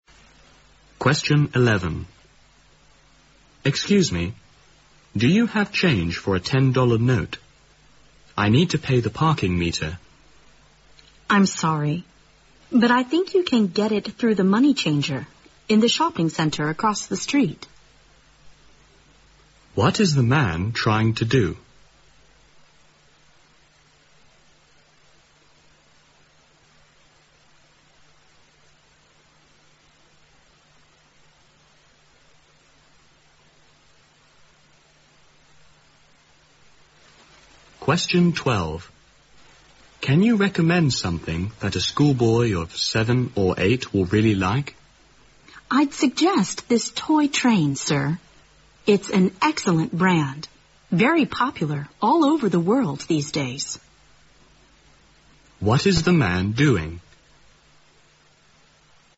在线英语听力室036的听力文件下载,英语四级听力-短对话-在线英语听力室